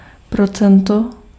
Ääntäminen
US : IPA : [pɚ.ˈsɛn.tɪdʒ]